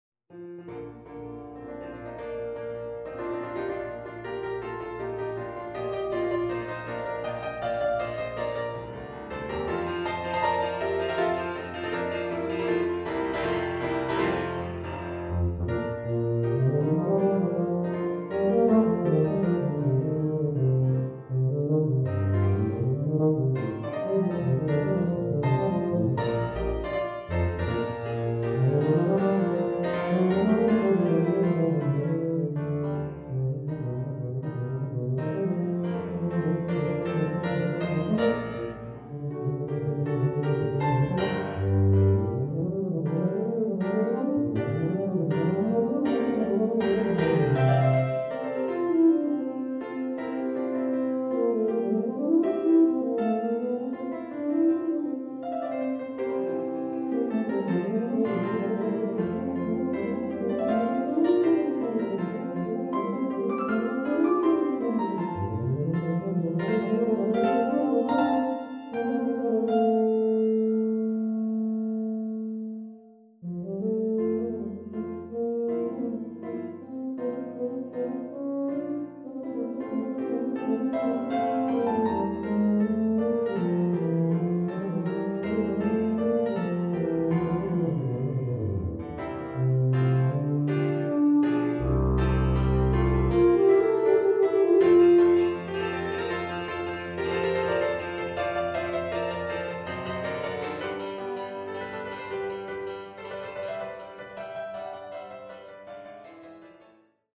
Voicing: Tuba